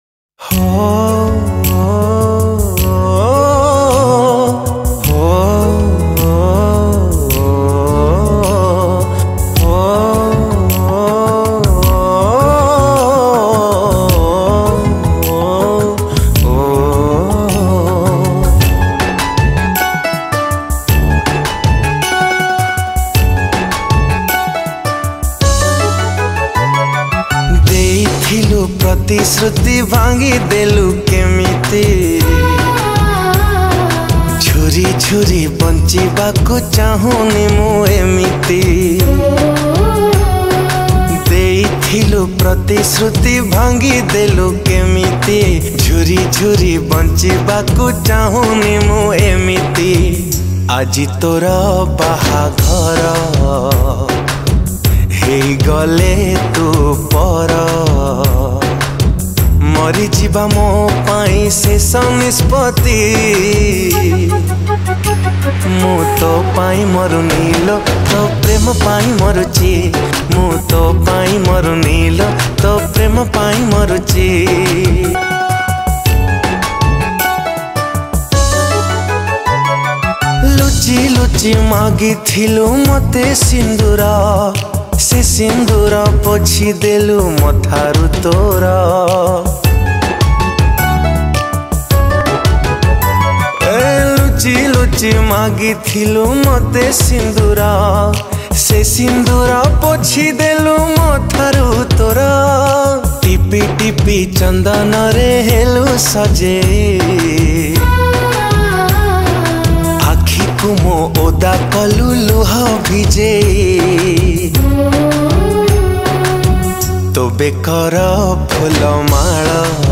Odia Sad Romantic Songs